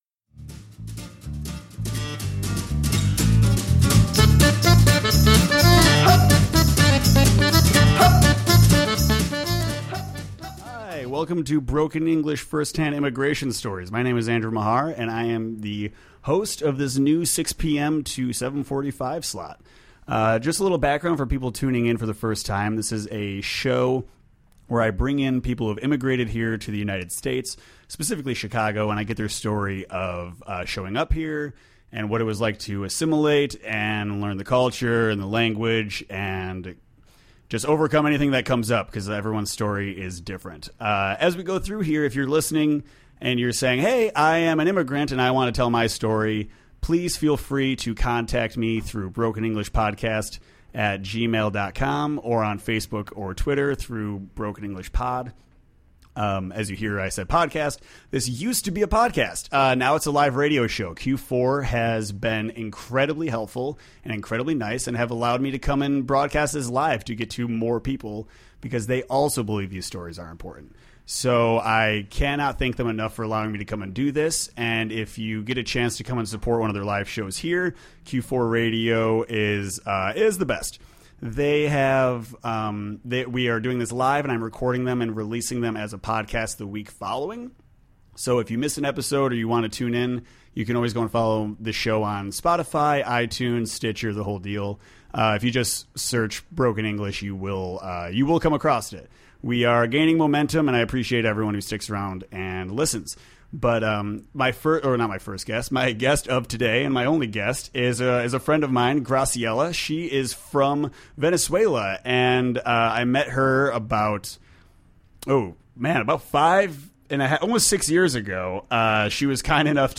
We air live on 1680am every Wednesday with a new guest every week.
Our quality has increased and I’m excited to share this new story after 2 years.